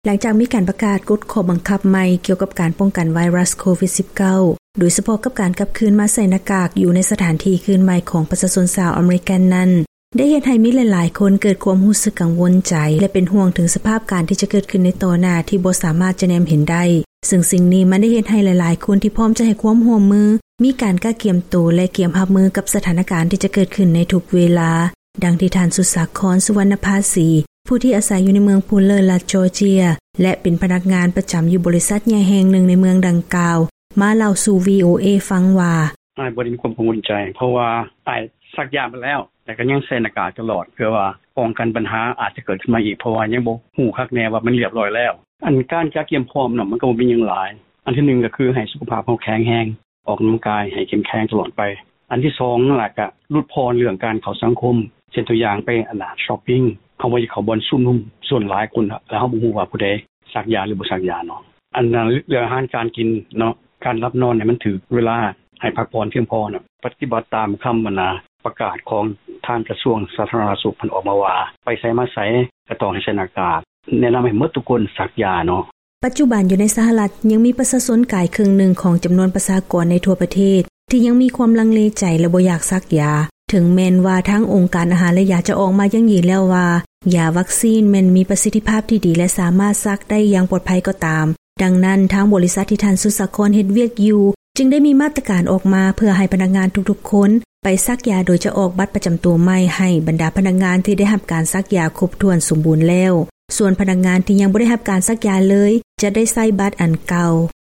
by ສຽງອາເມຣິກາ ວີໂອເອລາວ